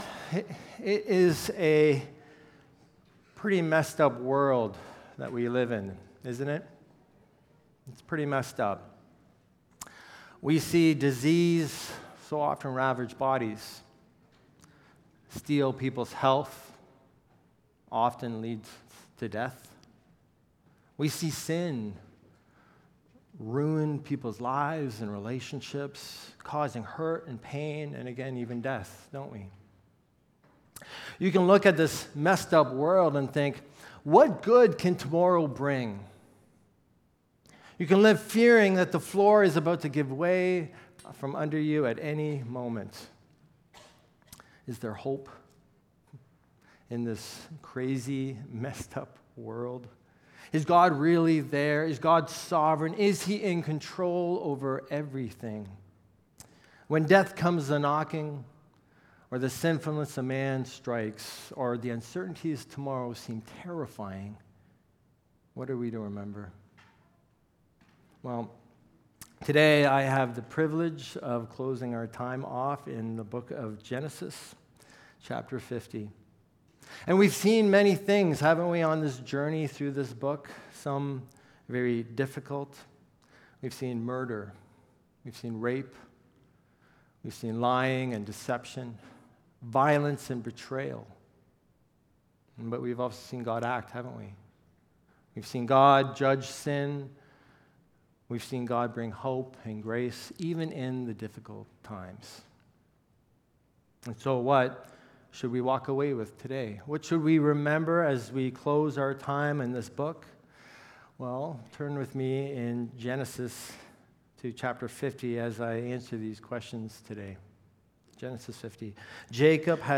God, Man, and a Messed Up World | Genesis 50 | Knollwood Baptist Church
No matter how broken the world feels, this sermon calls us to trust, forgive, and hope—because God is not absent or indifferent.